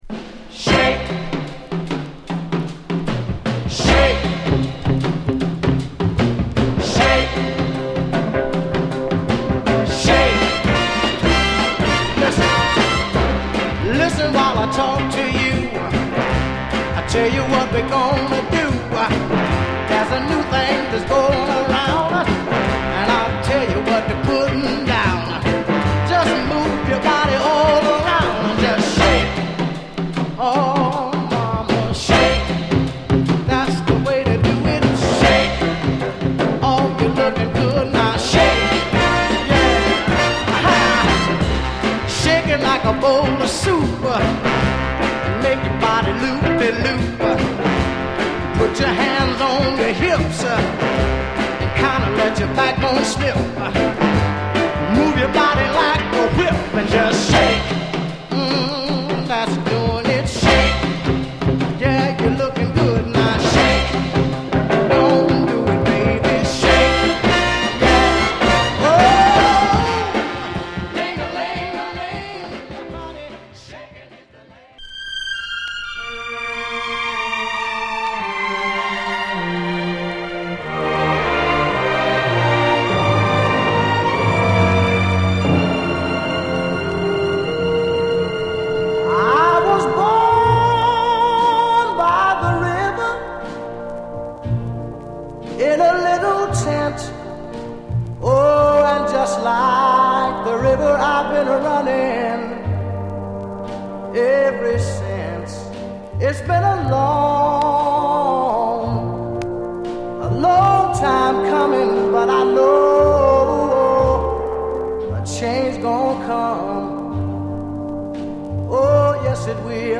*USオリジナル/Mono盤/ディープグルーヴ有/黒ラベル